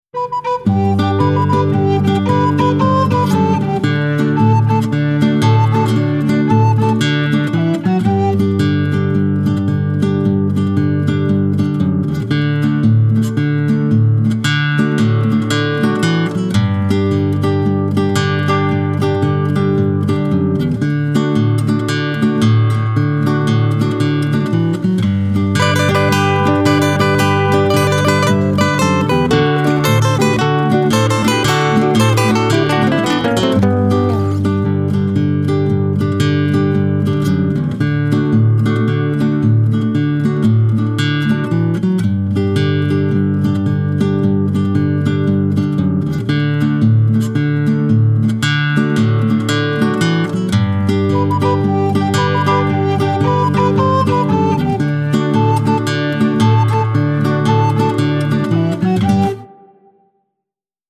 Ofrecemos también una versión solamente instrumental